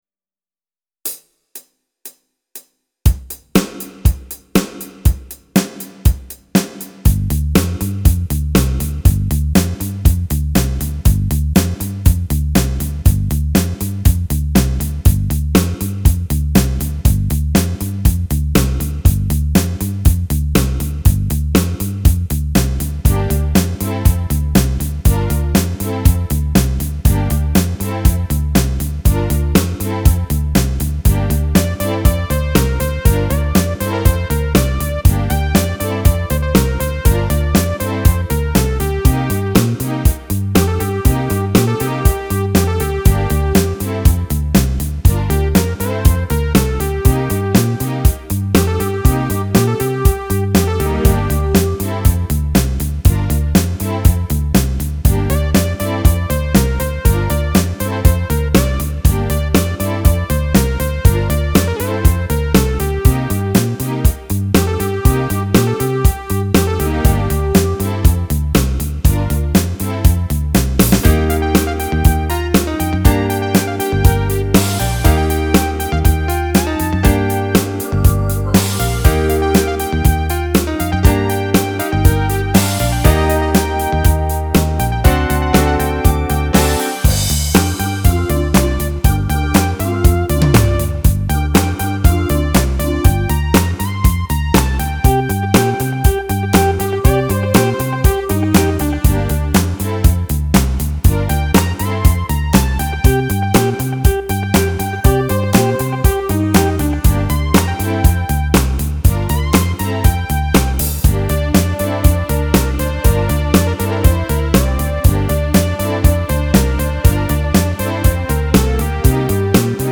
thSVMV6OJH  Download with Lead